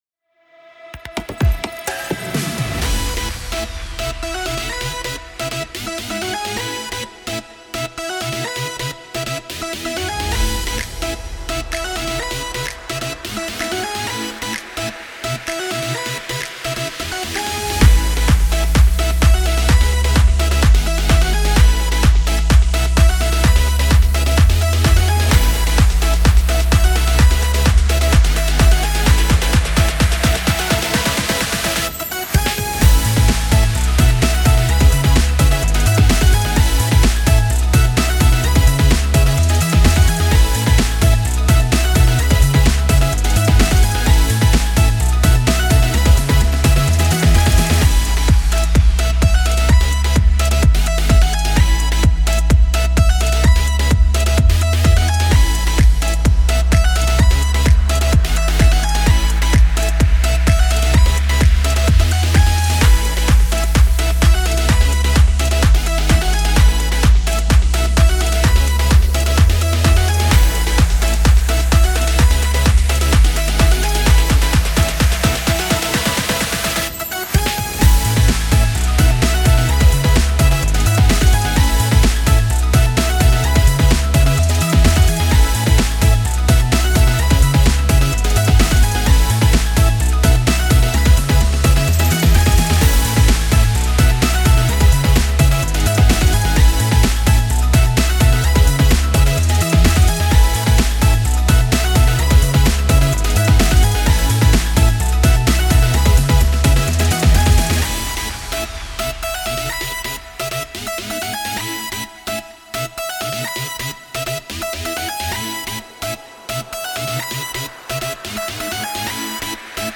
かっこいい/明るい/EDM
夏をイメージした明るい雰囲気の曲です。